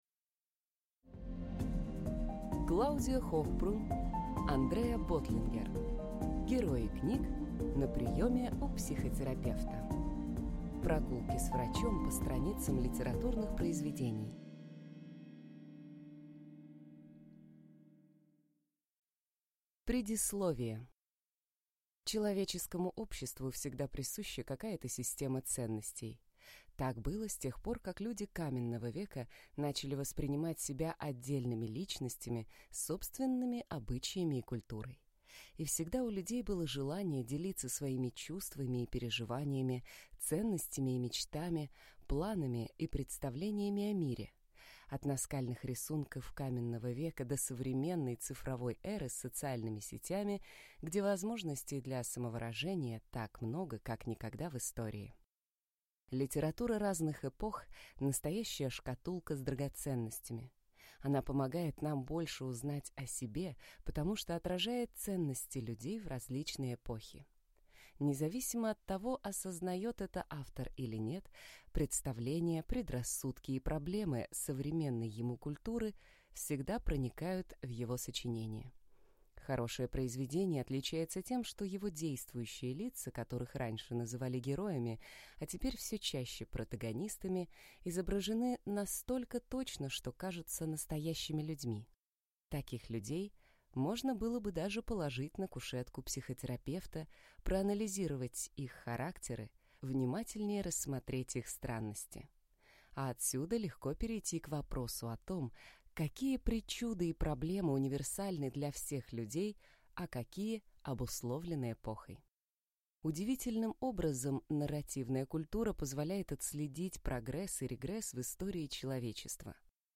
Аудиокнига Герои книг на приеме у психотерапевта. Прогулки с врачом по страницам литературных произведений. От Ромео и Джульетты до Гарри Поттера | Библиотека аудиокниг